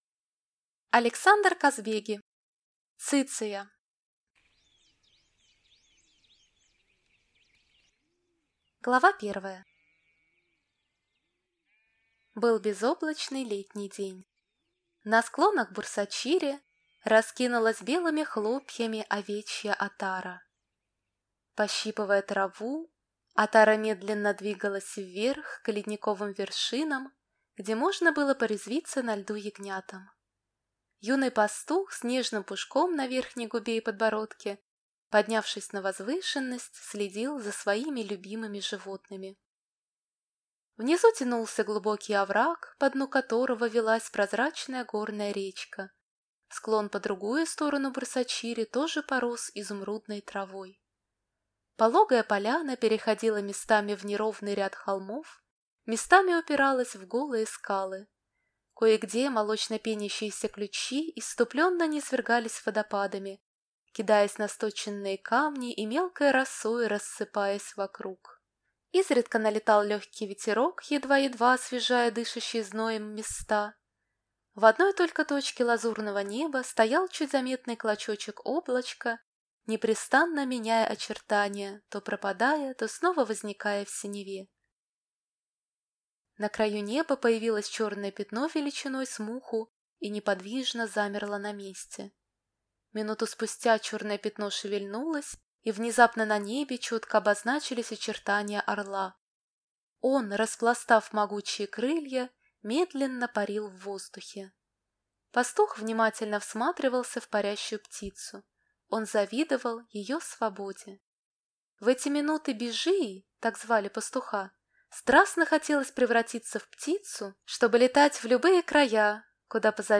Аудиокнига Циция | Библиотека аудиокниг